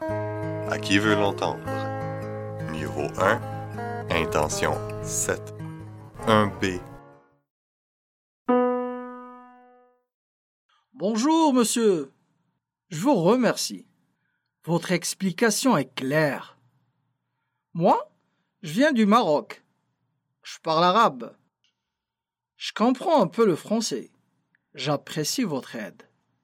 Pronoms personnels conjoints : Associer [ʒ] devant un mot commençant par une consonne sonore et [ʃ] devant un mot commençant par une consonne sourde au pronom sujet je o